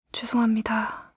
알림음 8_죄송합니다2-여자.mp3